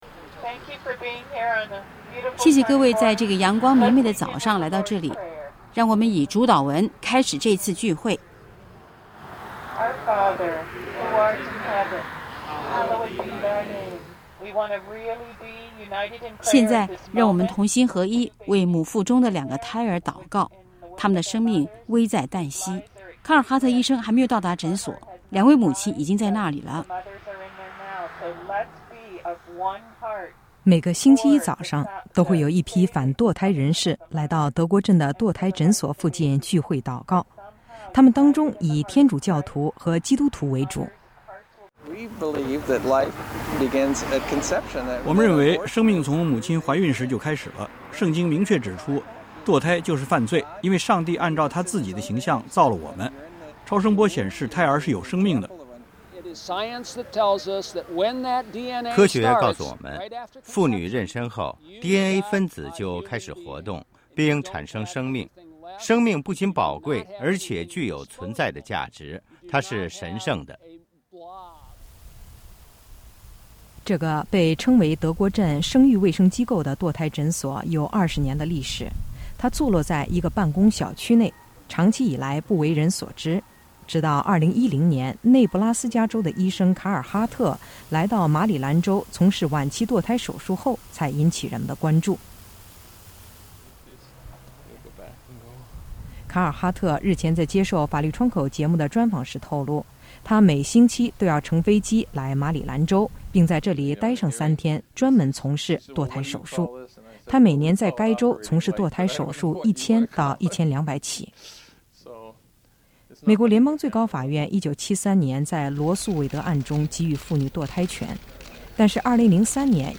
这可以通过围绕马里兰州德国镇堕胎诊所的争端而窥见一斑。法律窗口节目的记者最近在这个堕胎诊所外采访了集会抗议的反堕胎人士，从中了解到反堕胎运动的诉求。